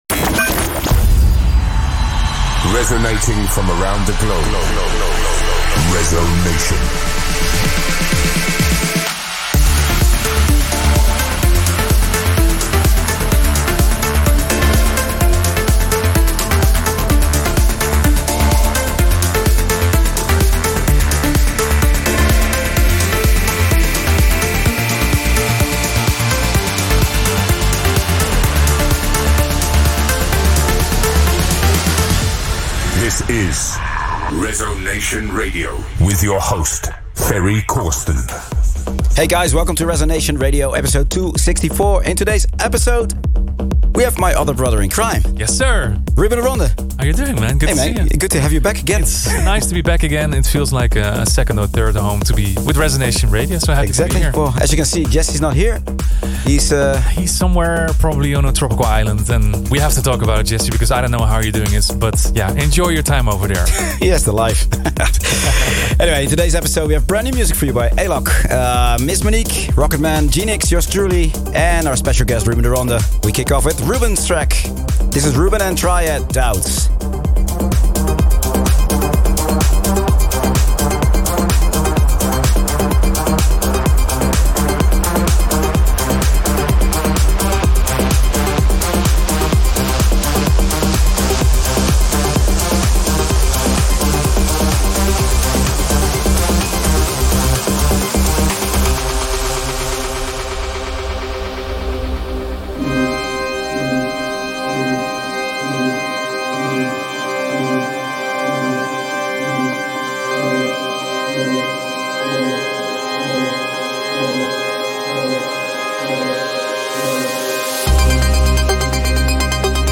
music DJ Mix in MP3 format
Genre: Trance